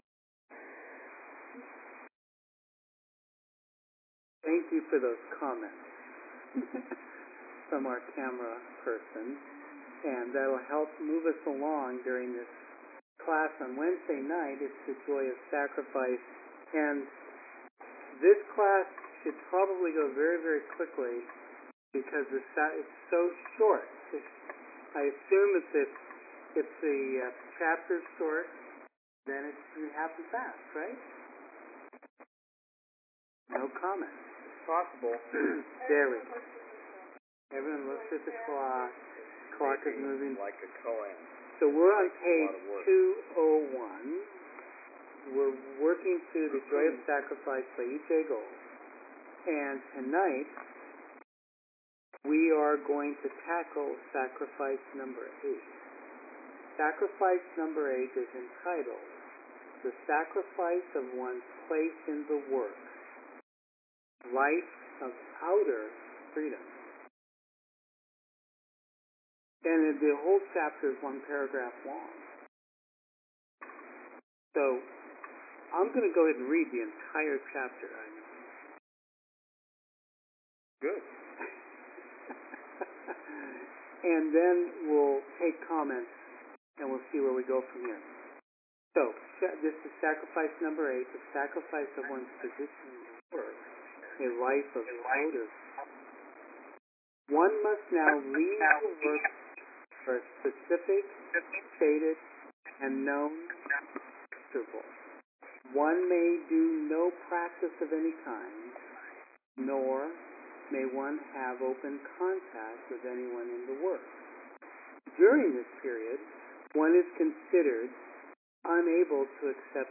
Here is the recording from the class: Audio Recording Class_10_Sept_07_2011 Need the book?